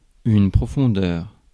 Ääntäminen
IPA: /pʁɔ.fɔ̃.dœʁ/